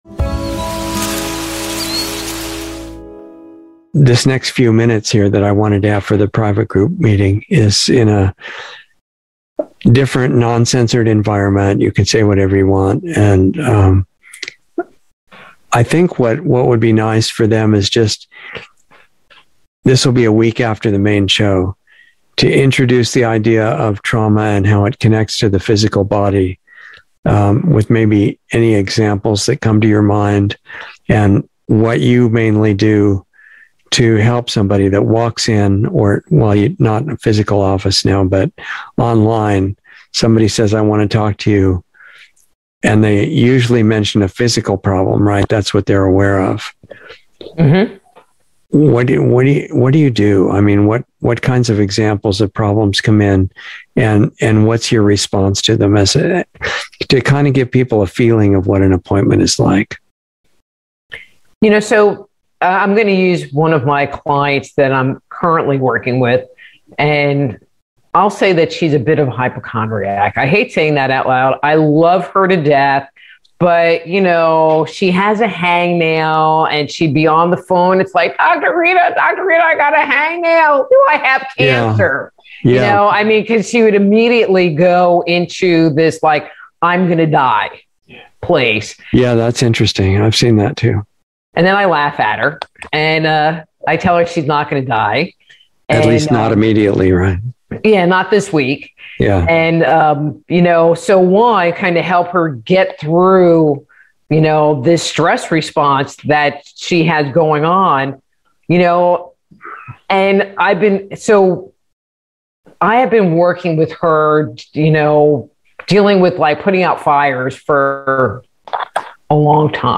Insider Interview 2/17/22